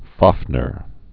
(fävnər, -nîr)